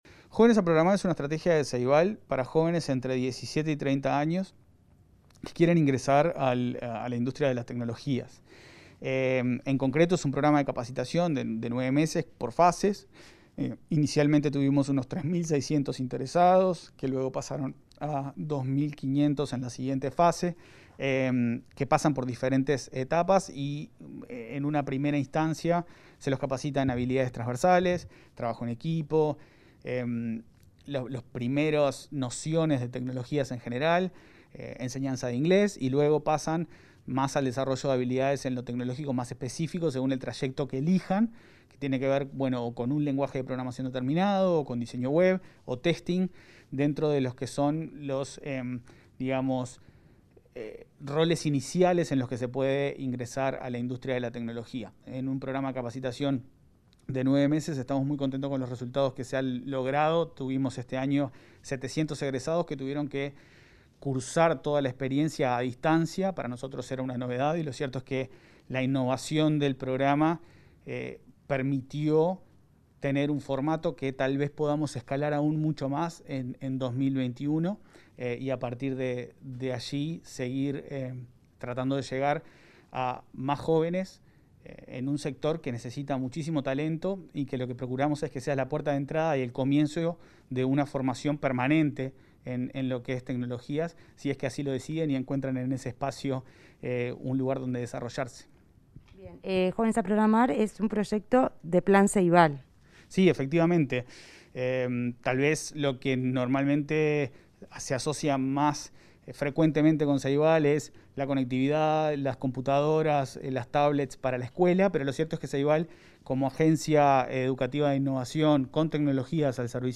Entrevista con el presidente del Plan Ceibal, Leandro Folgar